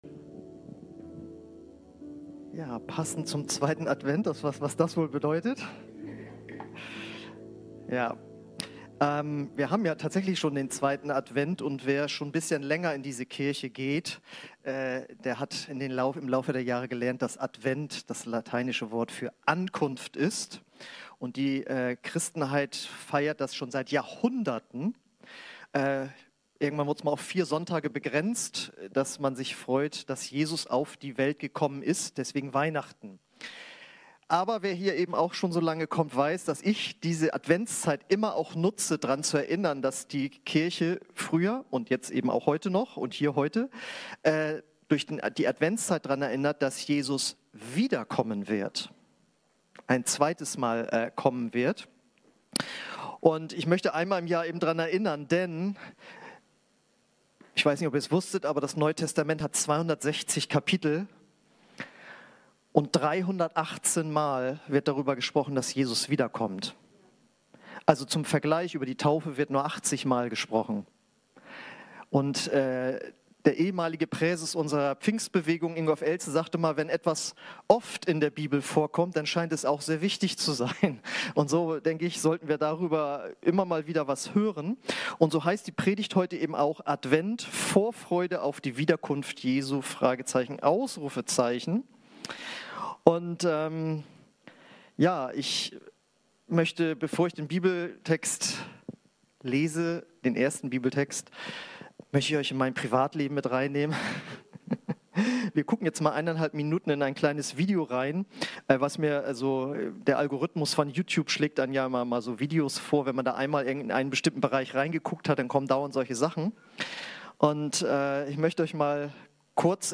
Predigten (v1) – OASIS Kirche